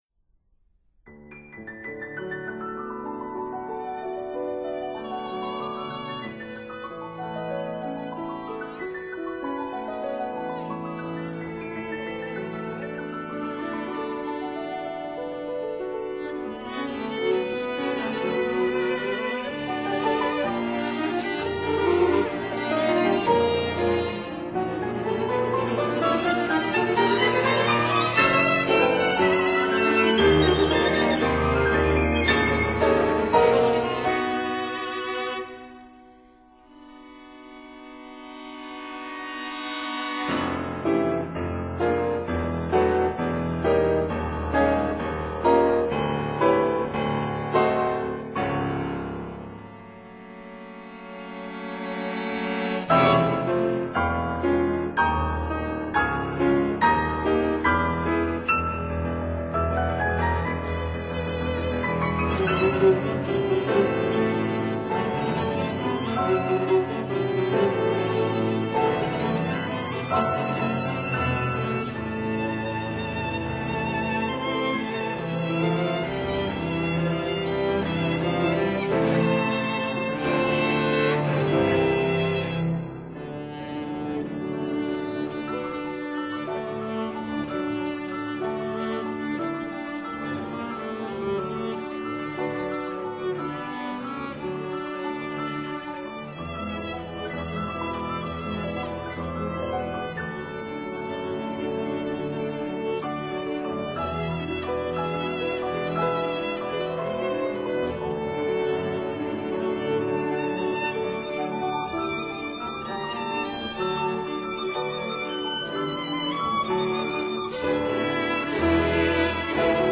violin
viola
cello
piano